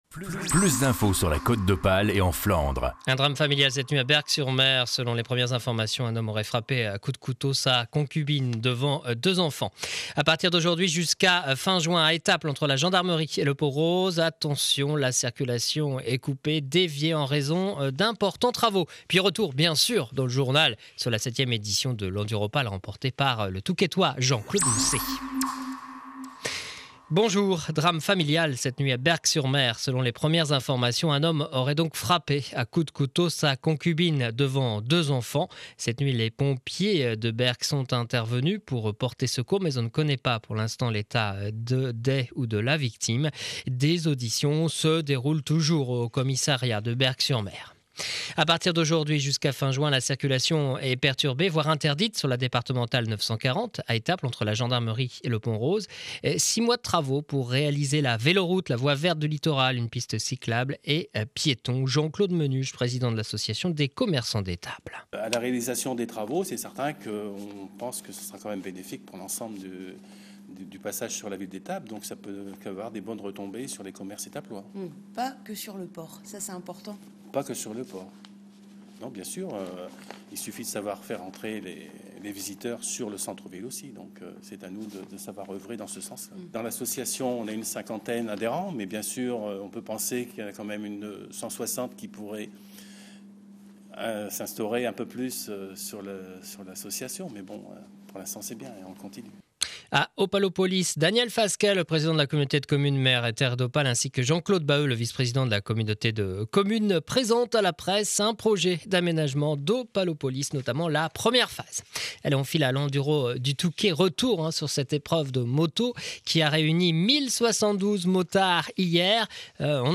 Edition de 12h journal de Montreuil sur mer